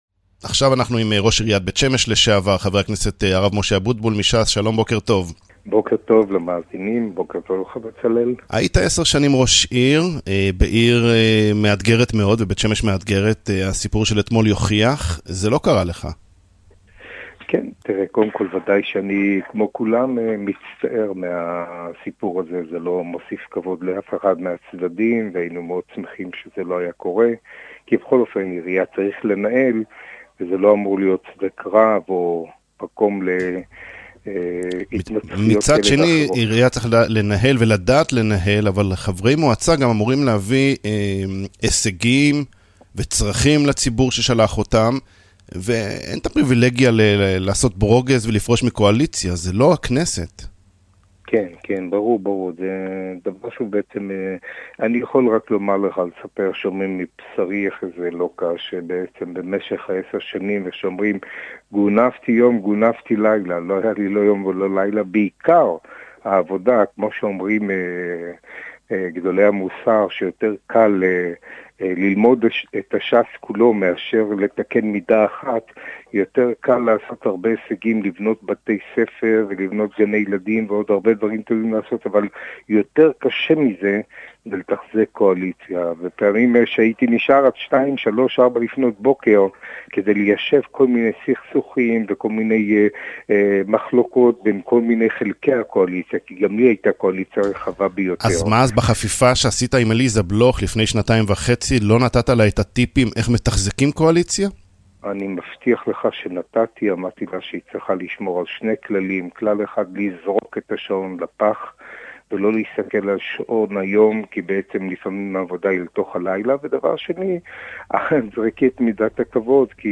הראיון המלא